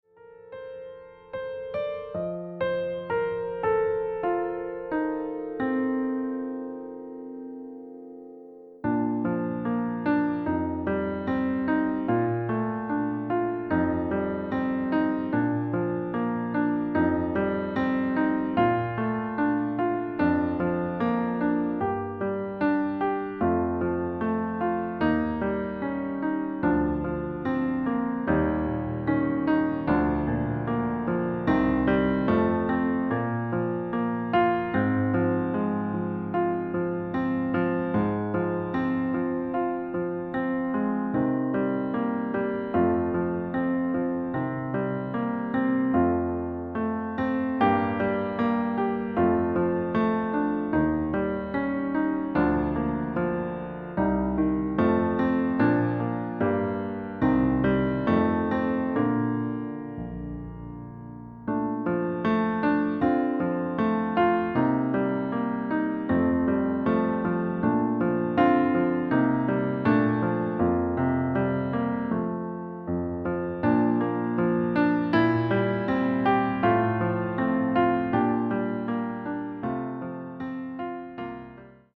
Klavierversion Flügel
• Tonart: C, D, Bb
• Das Instrumental beinhaltet NICHT die Leadstimme